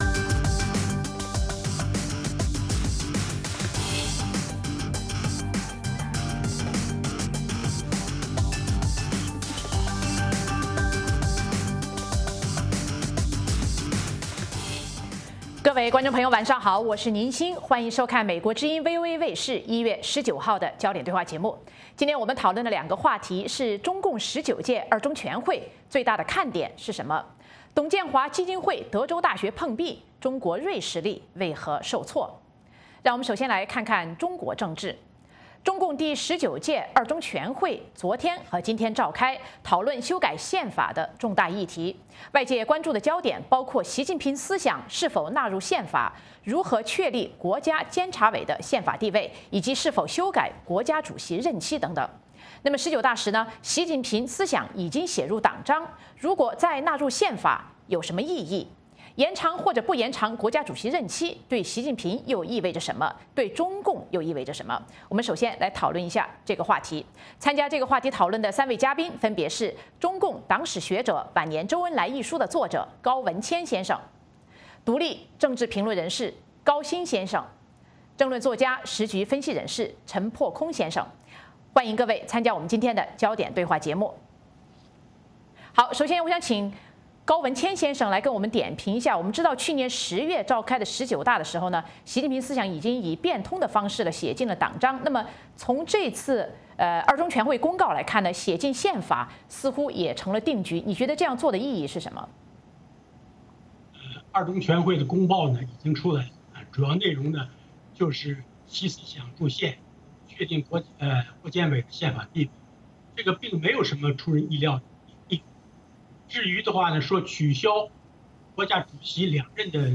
美国之音中文广播于北京时间每周一晚上8－9点重播《焦点对话》节目。《焦点对话》节目追踪国际大事、聚焦时事热点。邀请多位嘉宾对新闻事件进行分析、解读和评论。或针锋相对、或侃侃而谈。